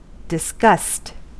Ääntäminen
US : IPA : [dɪs.ˈgʌst]